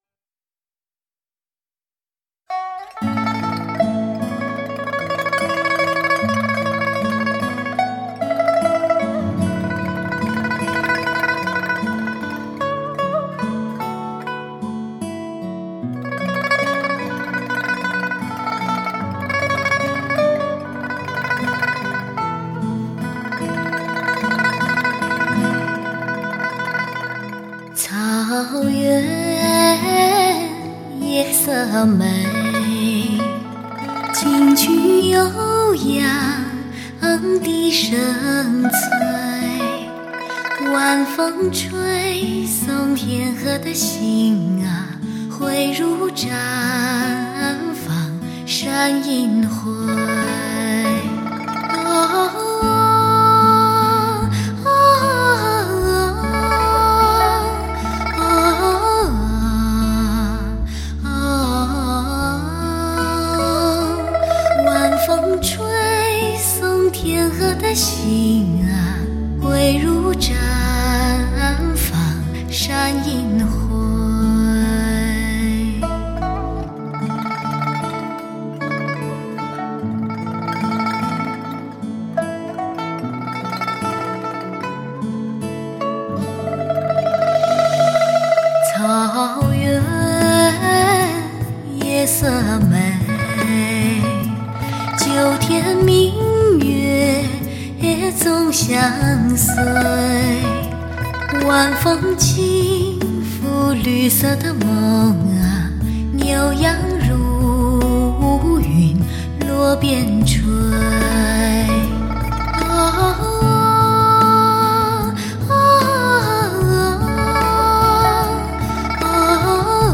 使得整张盘片在生产上呈现出前所未有的宽广，音质方面拥有无与伦比的通透，各类音色对比鲜明，
器乐演奏活灵活现，实乃碟之收藏宝鉴！